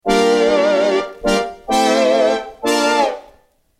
A great and unique "revival" soundbank including typical but original digital FM synthesis patches - Click here to read a detailed patches description
IMPORTANT NOTE: slight external reverb and chorus FXs have been added for the MP3 demos
US_Brass.mp3